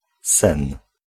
Ääntäminen
Ääntäminen Tuntematon aksentti: IPA: [s̪ɛ̃n̪] IPA: /sɛn/ Haettu sana löytyi näillä lähdekielillä: puola Käännös Ääninäyte Substantiivit 1. dream GenAm US 2. sleep UK US Suku: m .